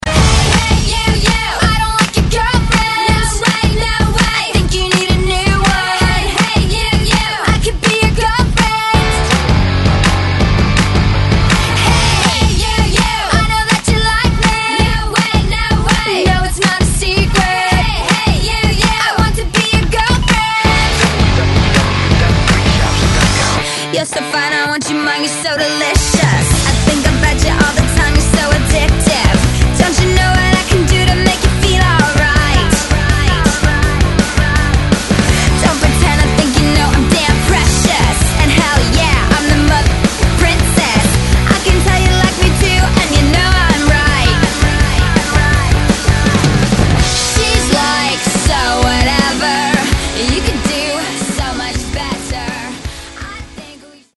non-stop dance hits